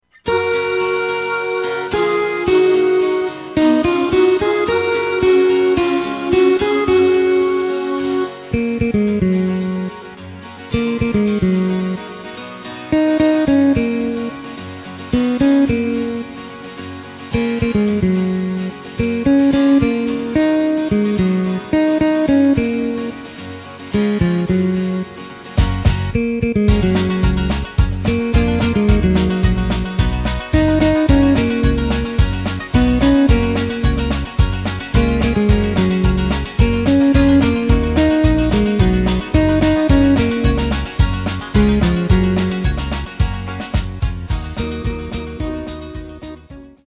Takt: 4/4 Tempo: 109.00 Tonart: F#
Weihnachtssong aus dem Jahr 2006!